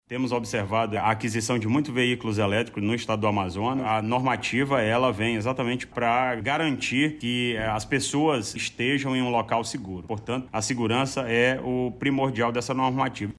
O comandante-geral do CBMAM, Orleilso Muniz, explica que a medida visa a segurança dessas estações, visto o crescimento no número de carros elétricos, no Amazonas.